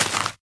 Sound / Minecraft / dig / grass1